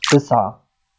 keyword-spotting
speech-commands